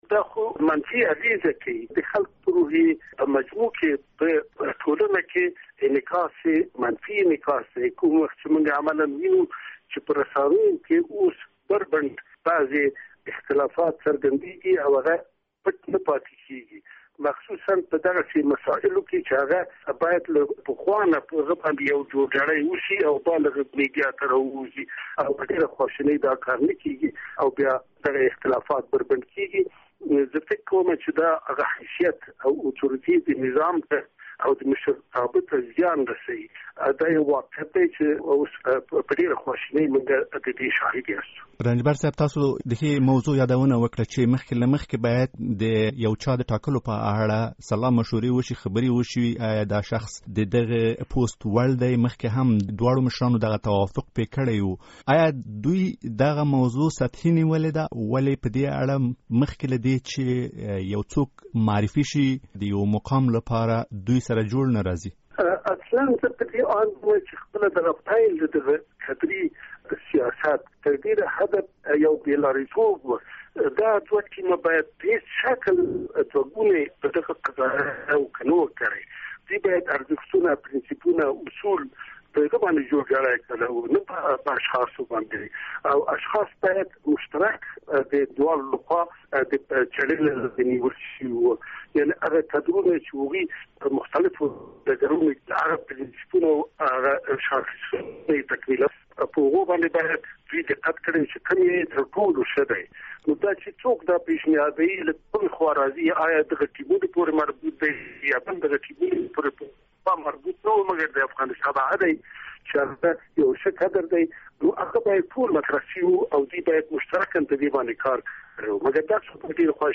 له کبیر رنجبر سره مرکه